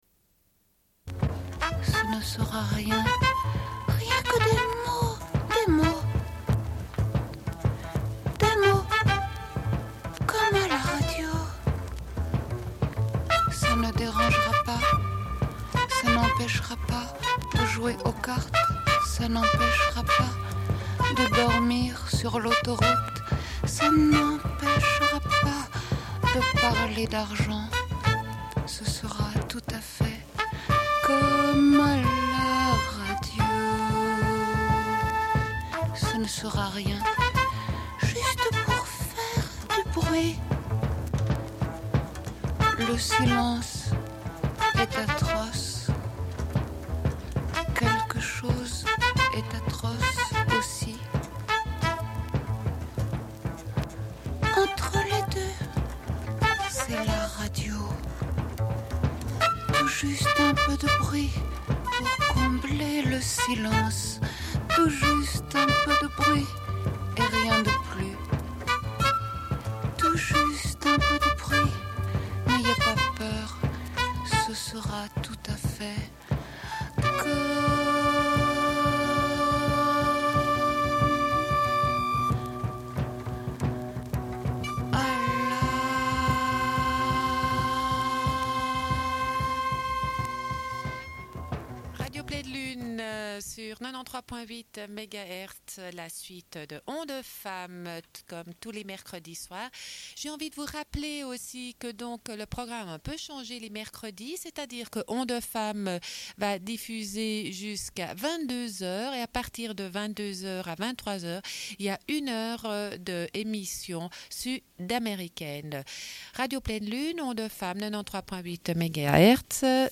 Une cassette audio, face B29:22